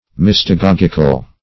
Search Result for " mystagogical" : The Collaborative International Dictionary of English v.0.48: Mystagogic \Mys`ta*gog"ic\, Mystagogical \Mys`ta*gog"ic*al\, a. Of or pertaining to interpretation of mysteries or to mystagogue; of the nature of mystagogy.
mystagogical.mp3